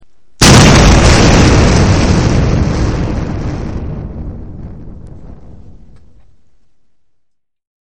Explosion2.mp3